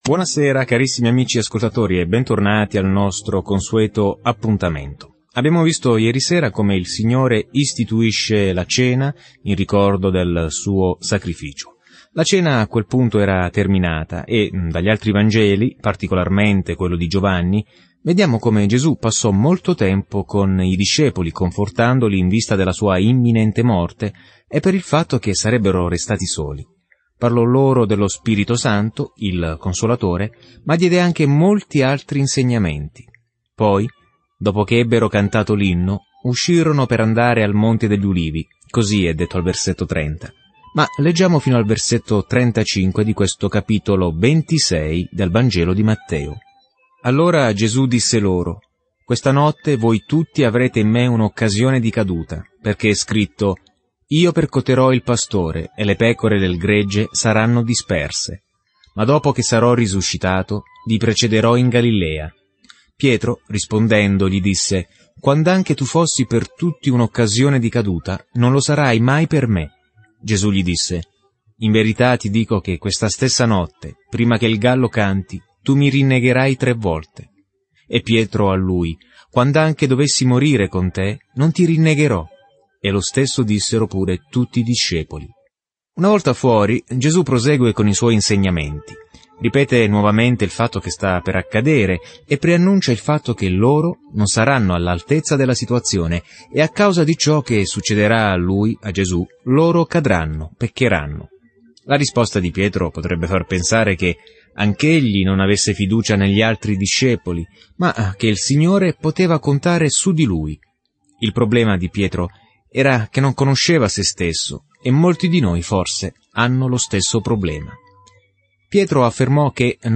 Viaggia ogni giorno attraverso Matteo mentre ascolti lo studio audio e leggi versetti selezionati della parola di Dio.